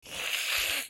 Cat Hiss Téléchargement d'Effet Sonore
Cat Hiss Bouton sonore